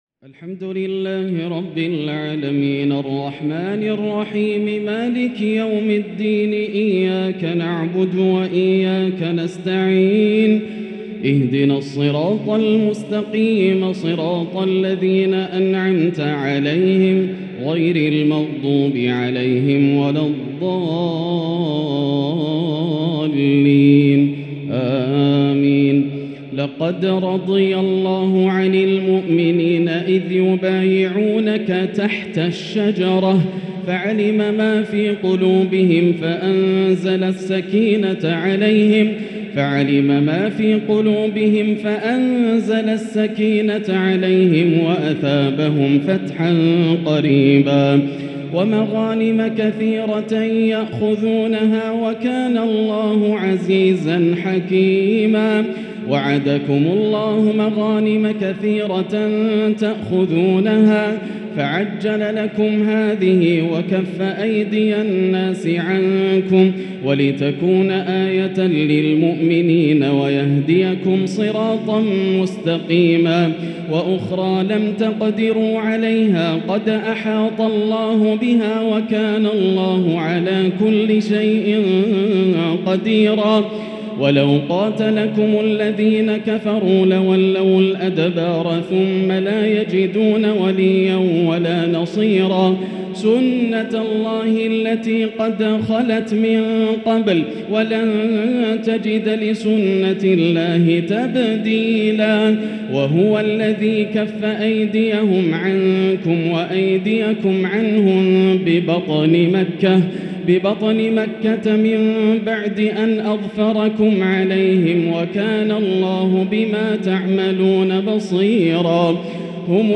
تراويح ليلة 27 رمضان 1444هـ من سور الفتح (18-29) و الحجرات و ق | Taraweeh prayer from Surah Alfath And Alhujurat Qaf 1444H > تراويح الحرم المكي عام 1444 🕋 > التراويح - تلاوات الحرمين